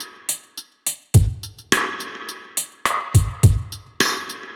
Index of /musicradar/dub-drums-samples/105bpm
Db_DrumKitC_Wet_105-01.wav